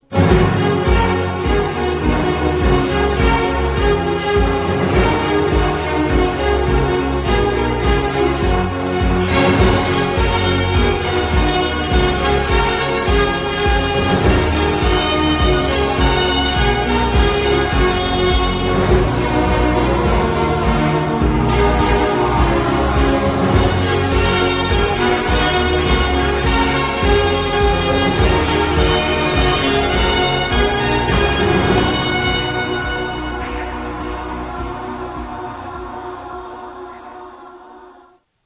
the bouncy, original opening theme!